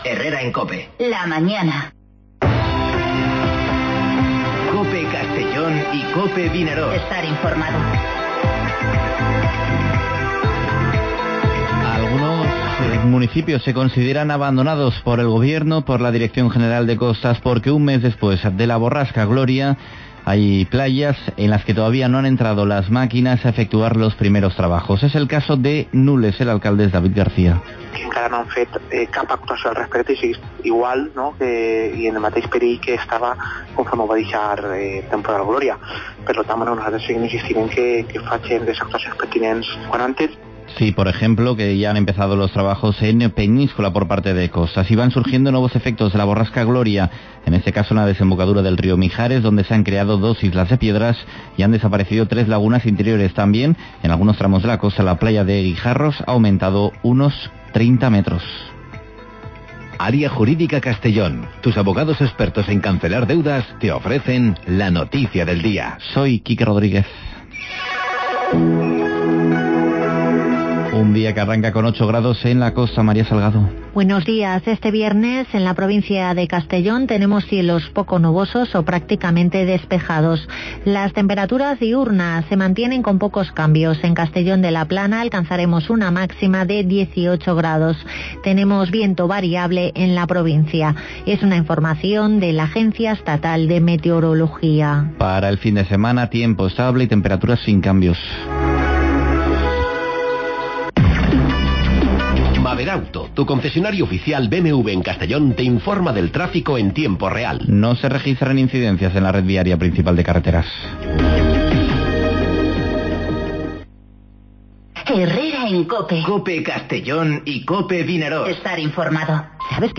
Informativo Herrera en COPE en la provincia de Castellón (21/02/2020)